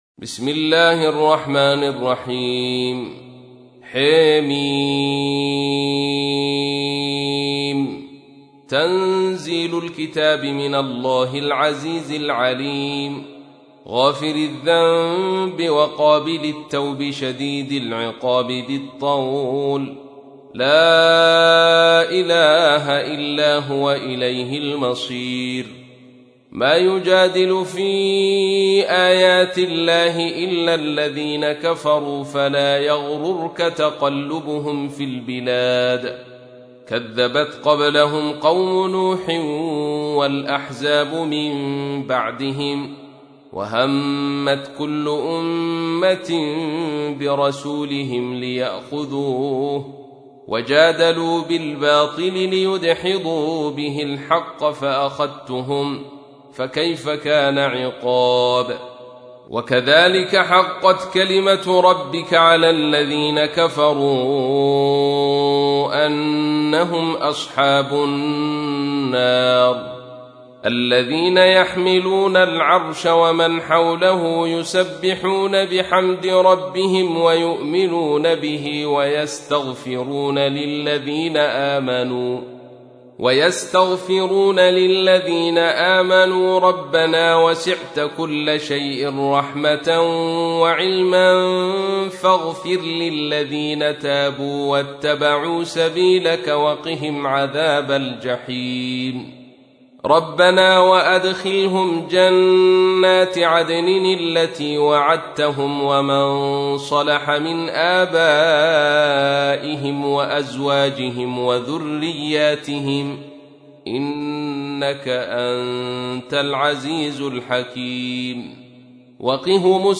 تحميل : 40. سورة غافر / القارئ عبد الرشيد صوفي / القرآن الكريم / موقع يا حسين